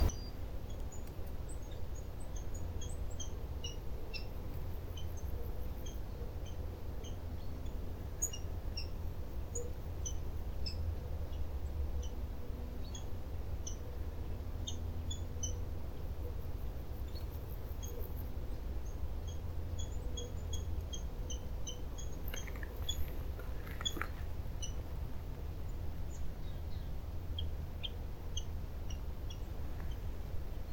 głos